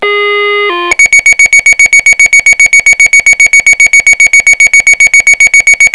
Pager Tones
MONROEFDPAGER[1].wav